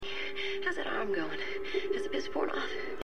A écouter  : les voix originales des acteurs principaux